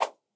quick transitions (3).wav